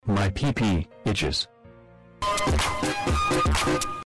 message-notification-alerts-sound-effect_1.mp3